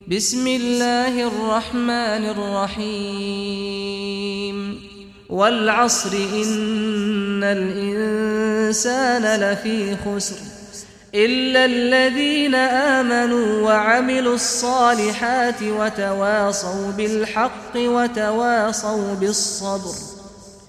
Surah Asr Recitation by Sheikh Saad al Ghamdi
Surah Asr, listen or play online mp3 tilawat / recitation in Arabic in the beautiful voice of Sheikh Saad al Ghamdi.